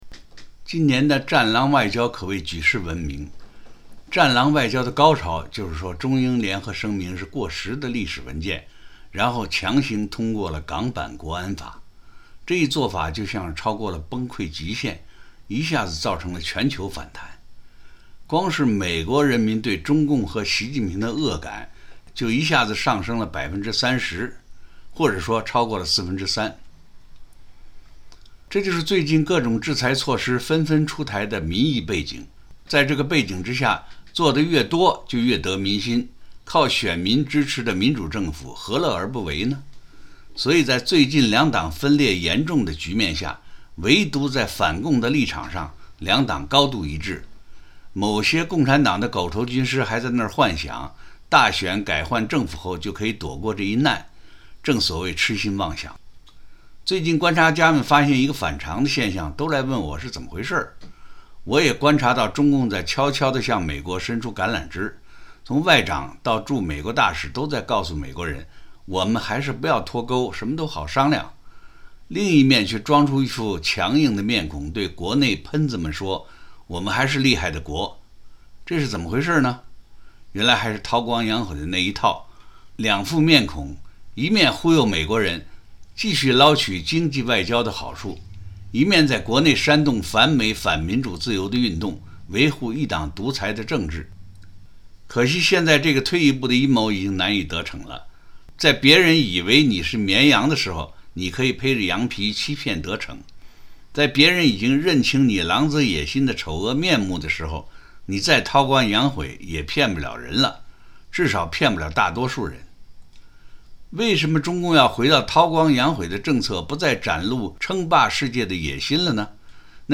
To hear Mr. Wei Jingsheng's related commentary, please visit:
(Written on August 8 and recorded on August 9, 2020.  Broadcasted by Radio Free Asia on August 10, 2020.)